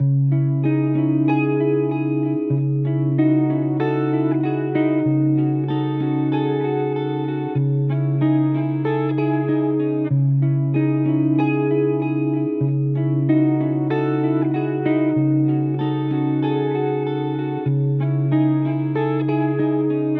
• 18 authentic guitar loops (with Stems: 90 total samples)